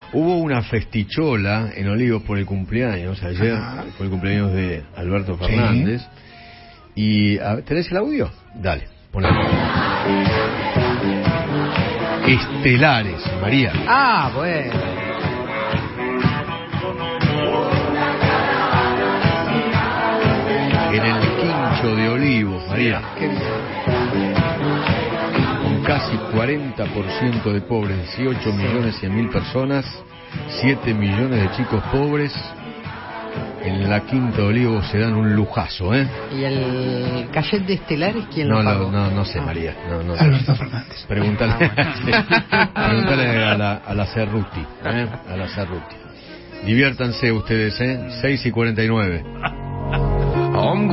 comnetario.mp3